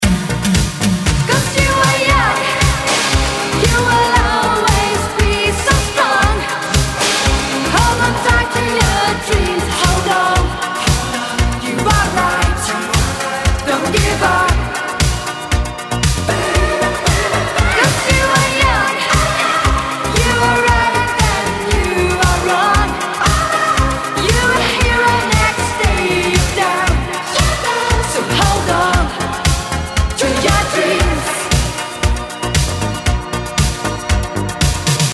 жанр, категория рингтона ЖАНР: РИНГТОНЫ 80е-90е